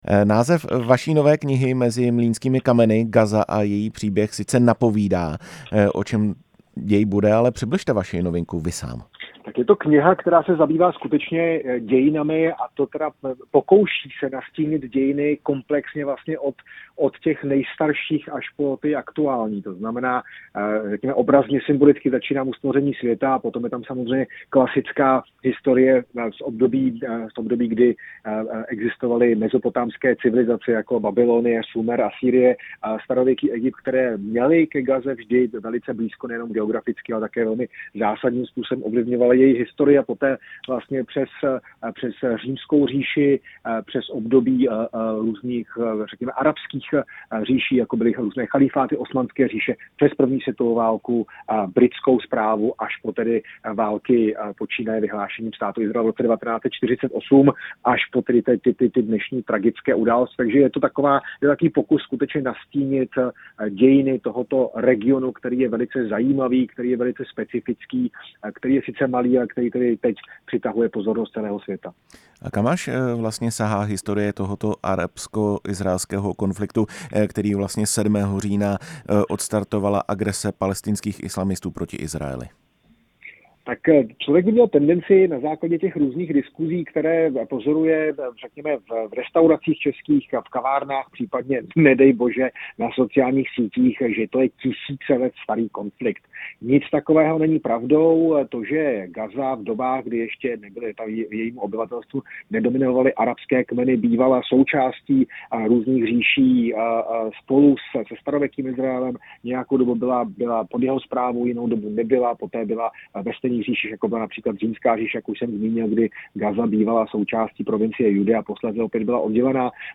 Jmenuje se Mezi mlýnskými kameny: Gaza a její příběh. Autor se v ní zaměřil na historii a současnost tohoto válkou zmítaného regionu. Jakub Szántó byl hostem ve vysílání Radia Prostor.
Rozhovor s novinářem Jakubem Szánto